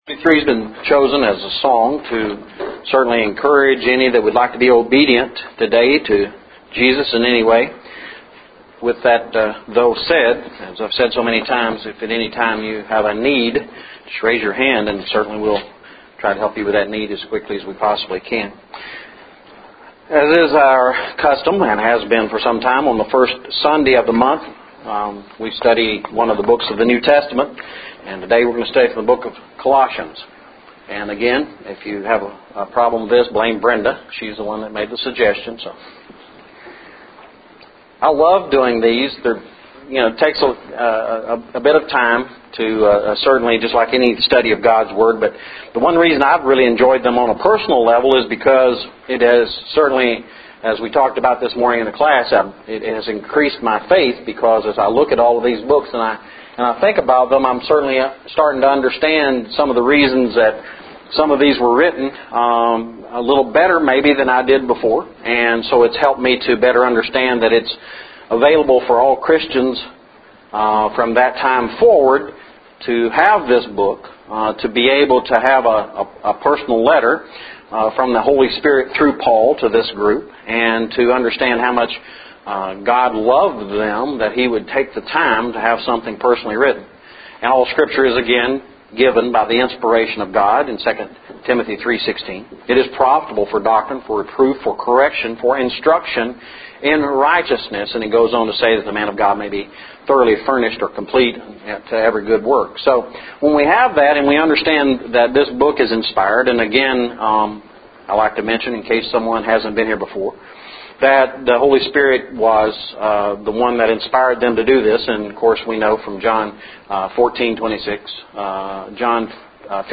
Colossians Lessons – 08/05/12